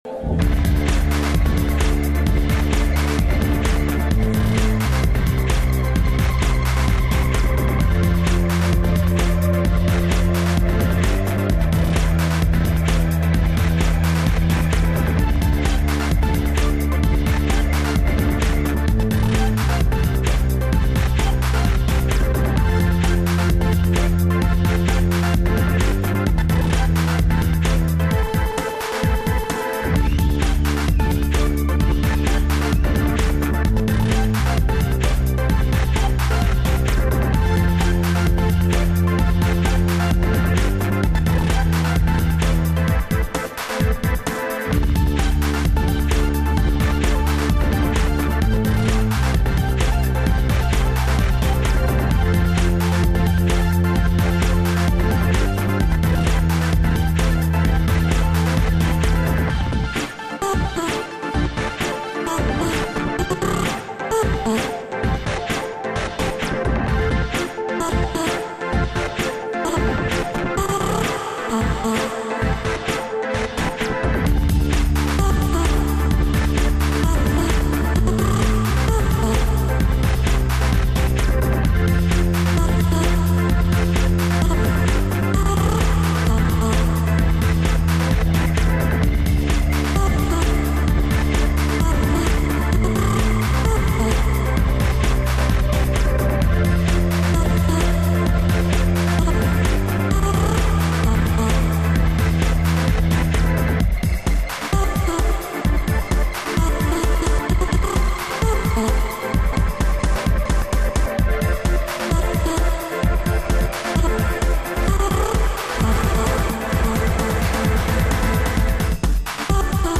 recorded at Debaser Medis, Stockholm
German Electronic artist